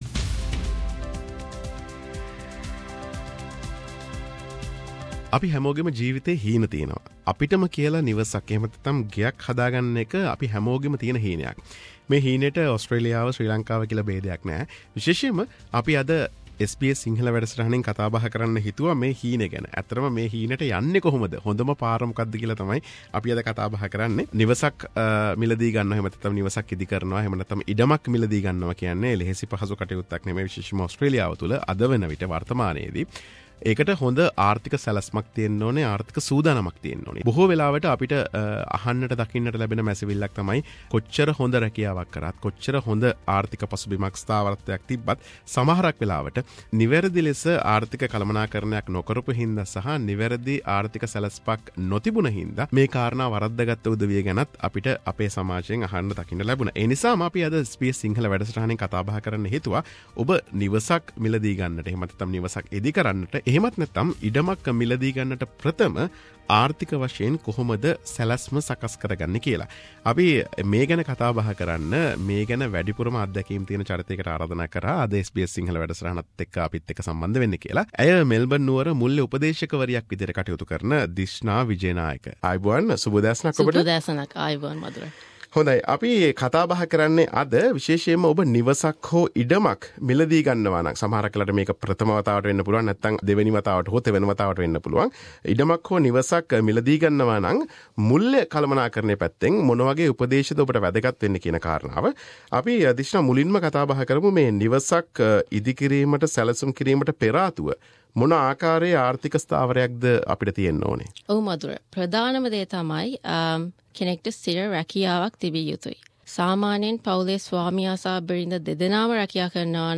SBS Sinhalese exclusive interview with Financial Planner